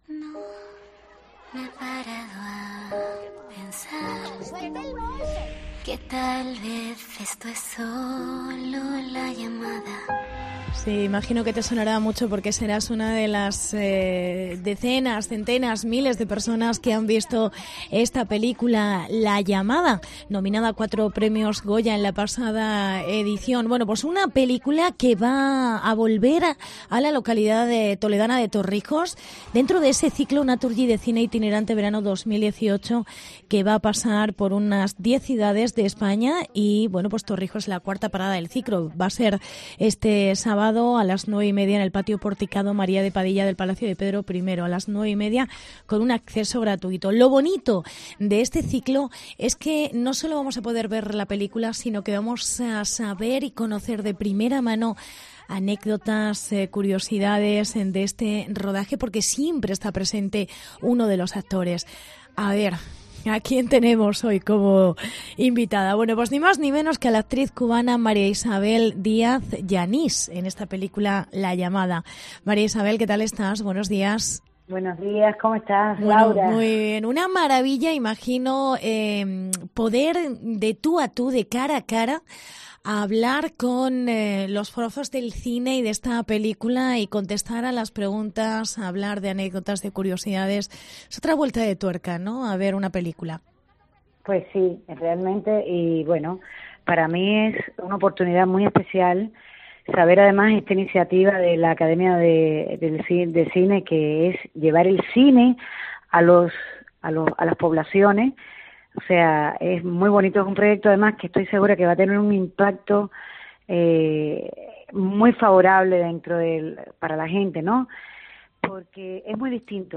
Hablamos con la actriz cubana